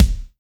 SYN_KICK.wav